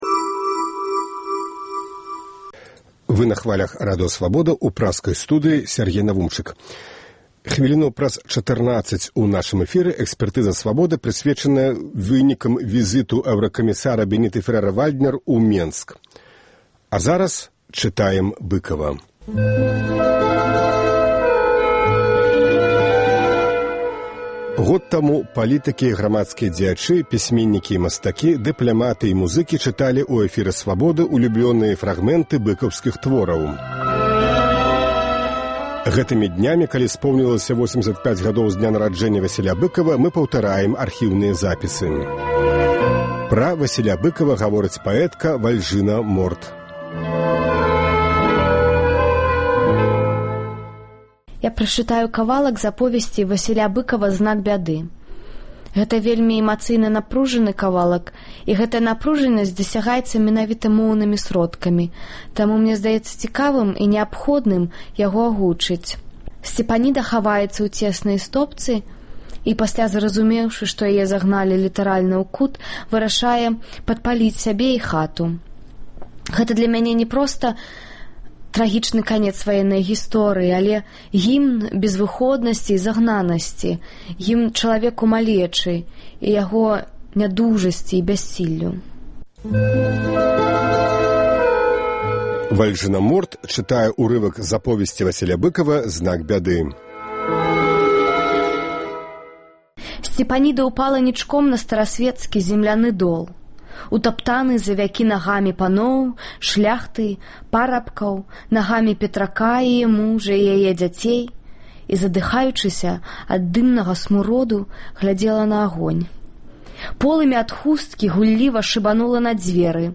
Год таму палітыкі і грамадзкія дзеячы, пісьменьнікі і мастакі, дыпляматы і музыкі чыталі ў эфіры Свабоды ўлюблёныя фрагмэнты быкаўскіх твораў. Гэтымі днямі, калі споўнілася 85-гадоў з дня нараджэньня Васіля Быкава, мы паўтараем архіўныя запісы. Сёньня Быкава чытаюць паэтка Вальжына Морт і паэт Уладзімер Някляеў.